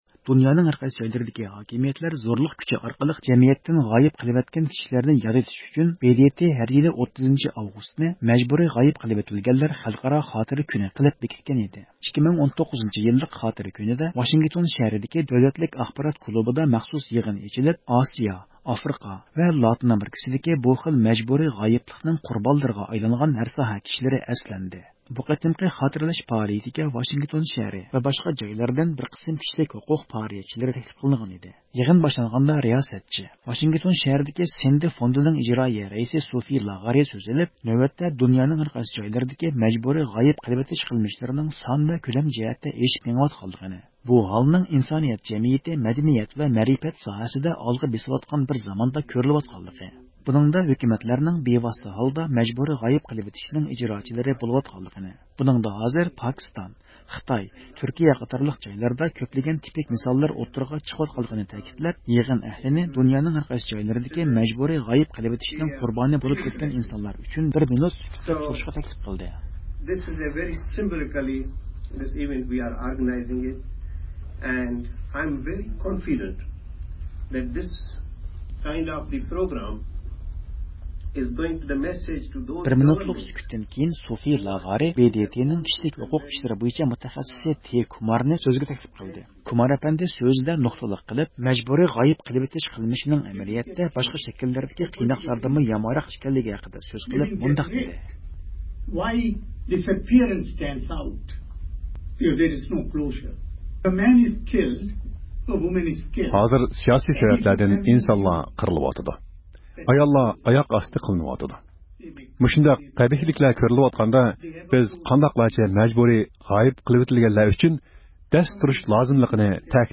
بۇ قېتىمقى خاتىرىلەش پائالىيىتىگە ۋاشىنگتون شەھىرى ۋە باشقا جايلاردىن بىر قىسىم كىشىلىك ھوقۇق پائالىيەتچىلىرى تەكلىپ قىلىنغان ئىدى.